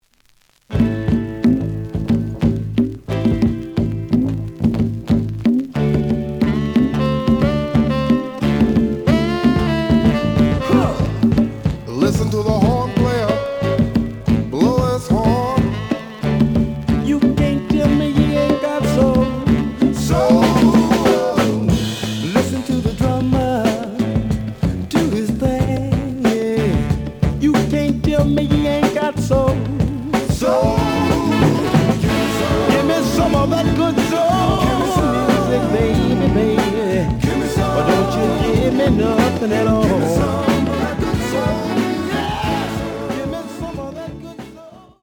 The audio sample is recorded from the actual item.
●Genre: Soul, 70's Soul
Some click noise on middle of both sides due to a bubble.